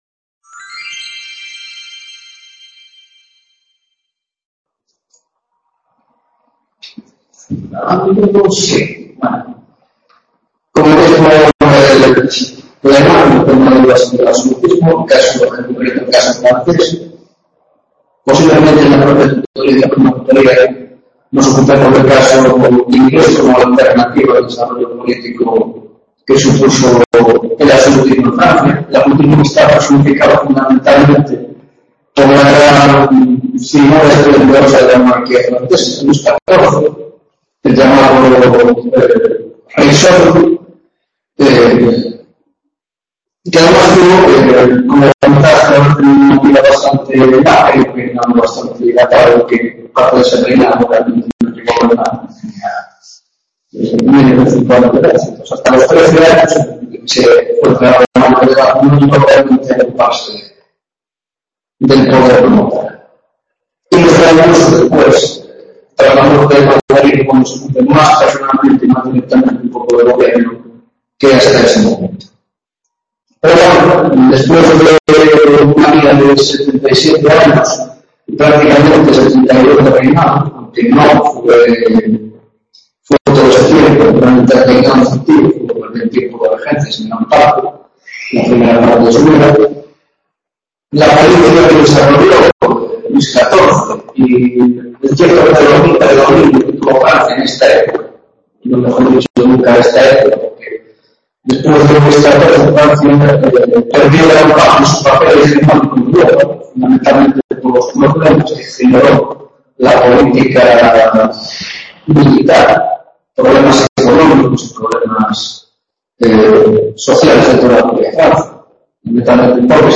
2ª tutoria de Historia de la Baja Edad Moderna - Absolutismo Luis XIV